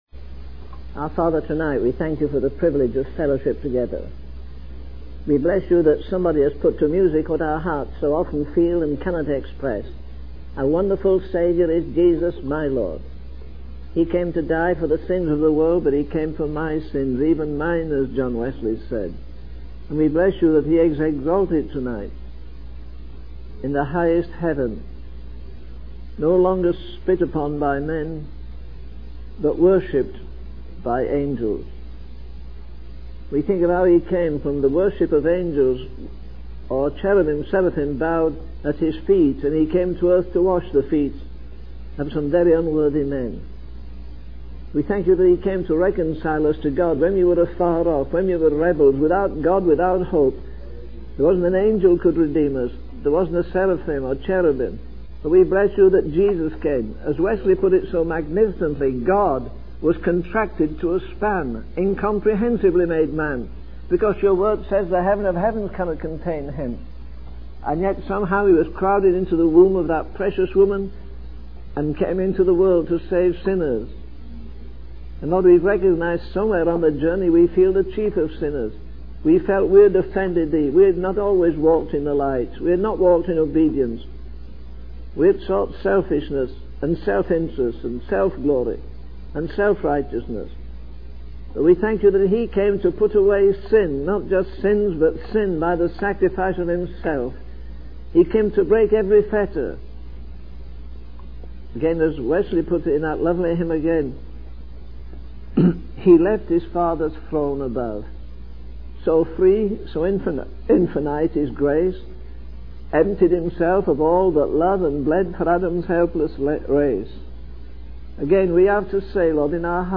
In this sermon, the speaker emphasizes the importance of walking in the light of God's truth and holiness in a dark and corrupt world. He highlights that all the attributes of Jesus are available to believers and that the possibilities of grace are vast.